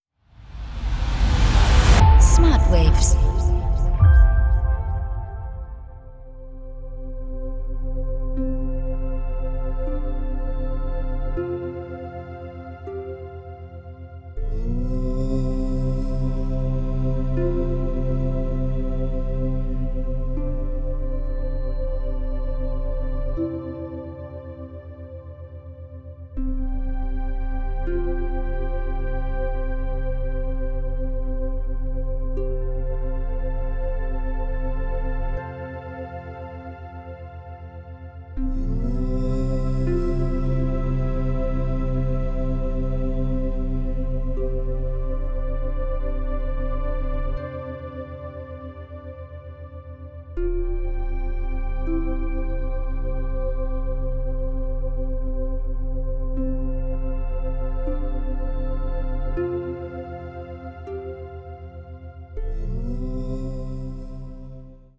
• Methode: Binaurale Beats
• Frequenz: 7 Hertz